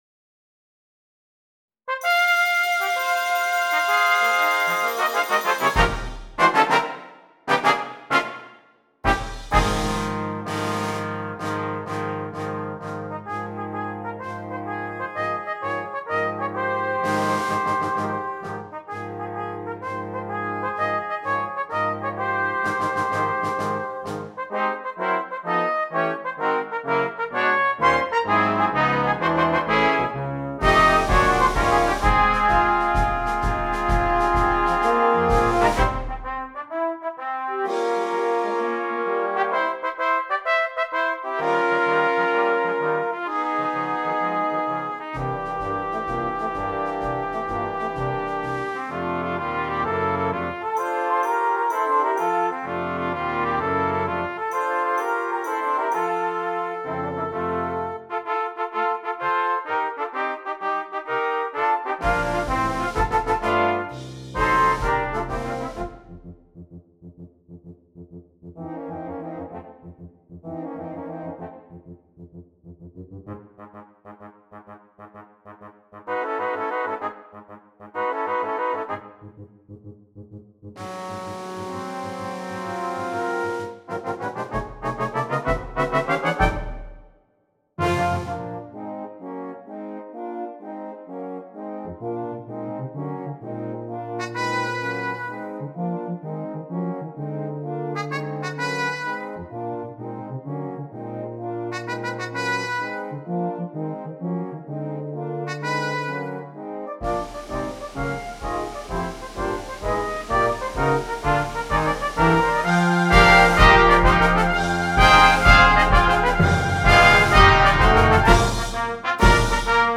Brass Choir (4.2.2.1.1.perc)